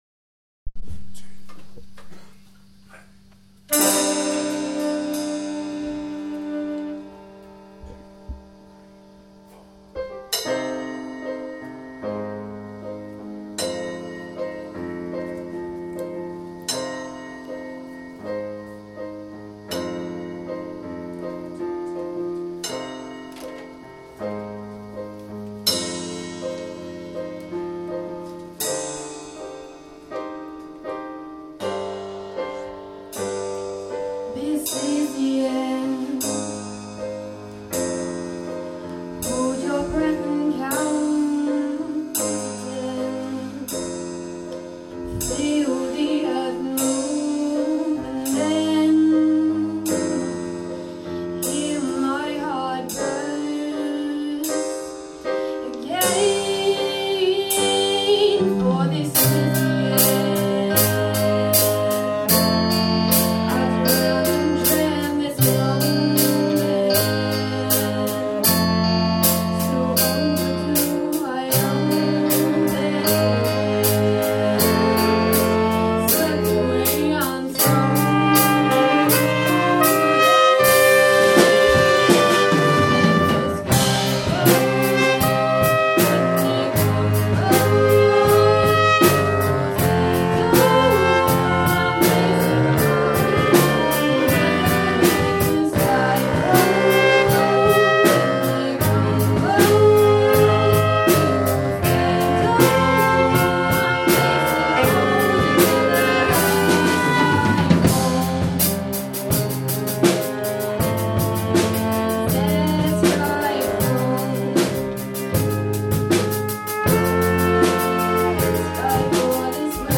vocals
gtr
bass
drums
piano